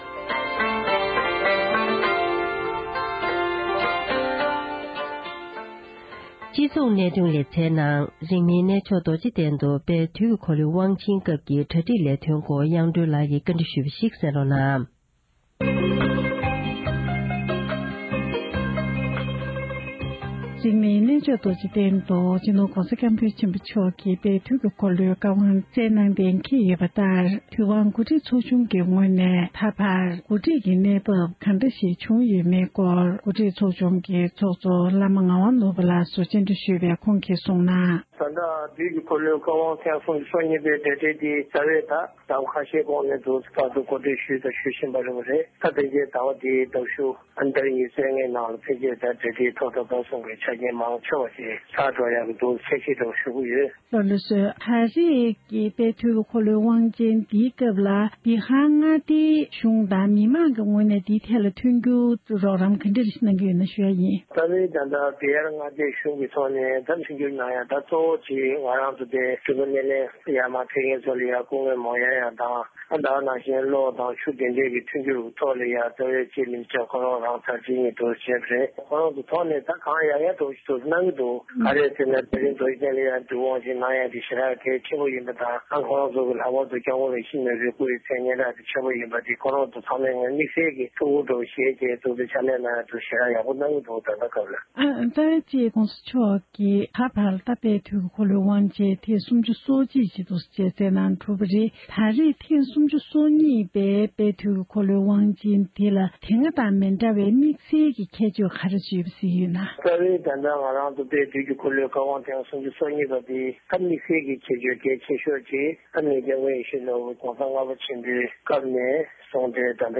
འབྲེལ་ཡོད་མི་སྣར་བཀའ་འདྲི་ཞུས་པ་ཞིག